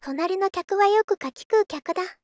sbv2-api - Infer only tts